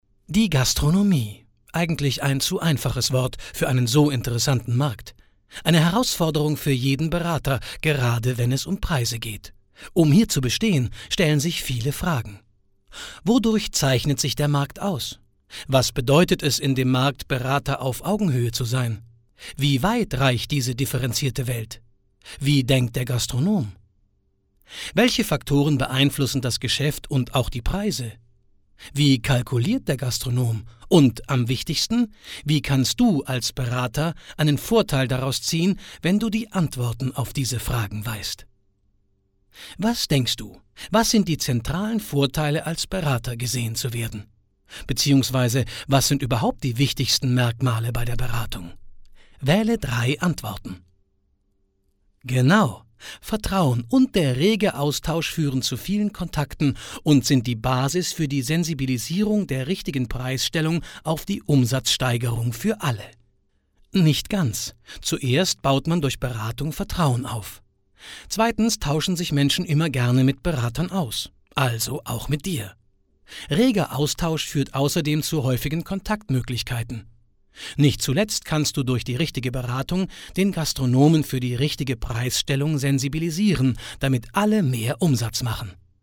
Mann
klingt ähnlich wie die Synchronstimme von Robert de Niro,hoher Wiedererkennungseffekt,edle Klangfarbe,breit gefächertes Spektrum,hohe Flexibilität,eigenes Studio.
Sprechprobe: eLearning (Muttersprache):